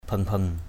/bʱɯŋ-bʱɯŋ/ 1.